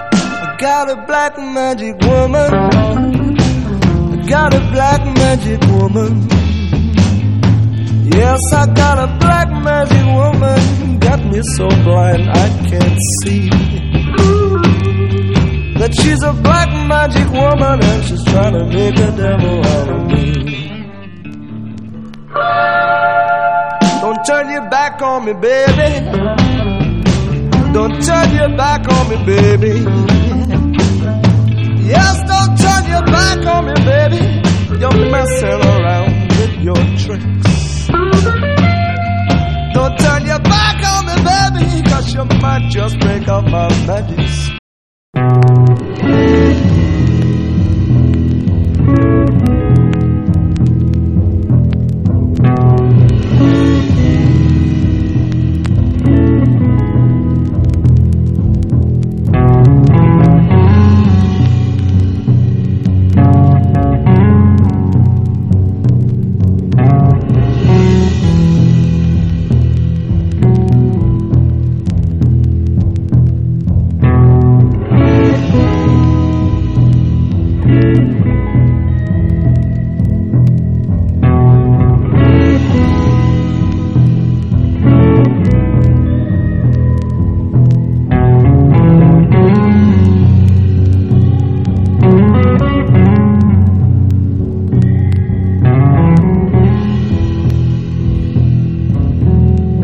ROCK / 60'S / BLUES ROCK / BLUES / GUITAR
ブリティッシュ・ブルースの殿堂
コクの深い激シブ・スロー・ブルース